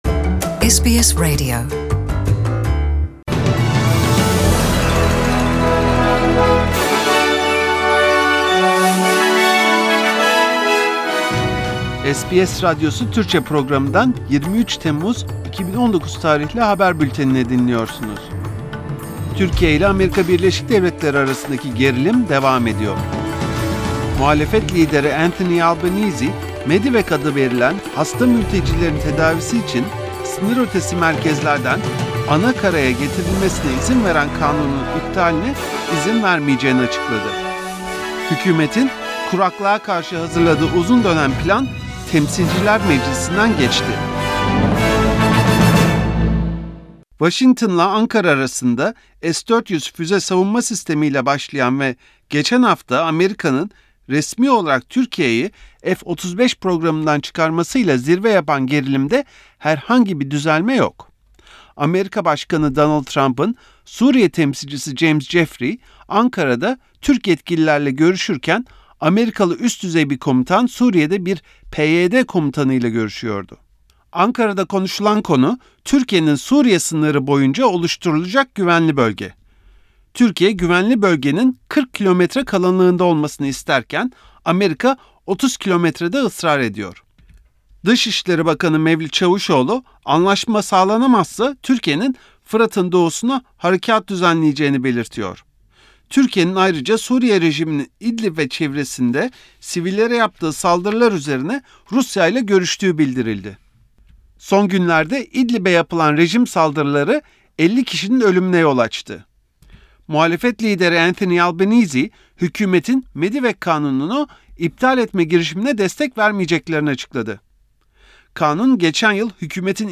SBS Radyosu Türkçe Programı'ndan Avustralya, Türkiye ve dünyadan haberler.